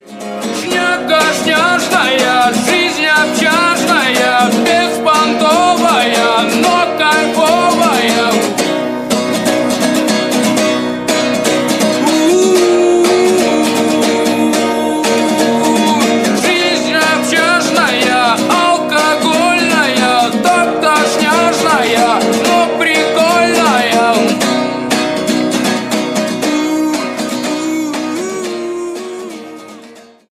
• Качество: 128, Stereo
гитара
мужской вокал
душевные
акустическая гитара